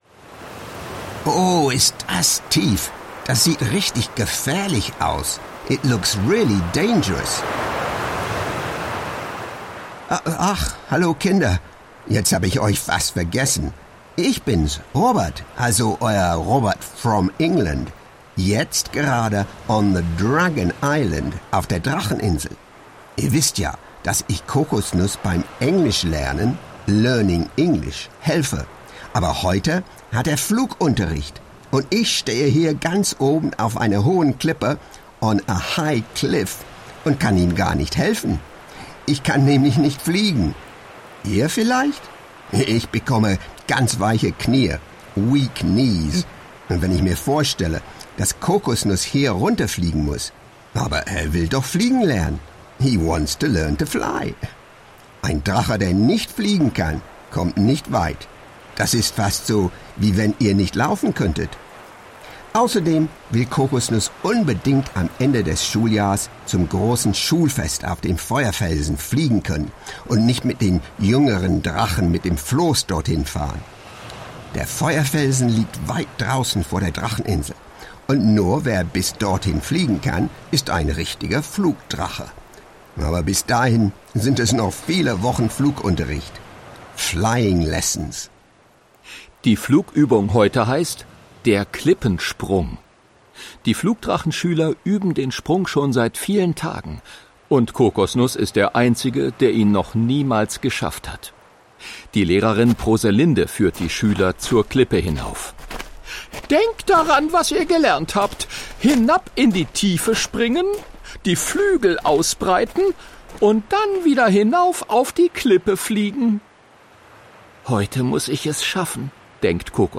Englisch lernen mit dem kleinen Drachen Kokosnuss. - Sprach-Hörbuch mit Vokabelteil
In jeder Lektion lernen die kleinen Zuhörerinnen und Zuhörer mit der Geschichte und einem kurzen Song eine neue Vokabelgruppe kennen. Der Erzähltext ist in Deutsch gesprochen, so dass auch für Englischanfängerinnen und -anfänger keine Verständnisprobleme bestehen.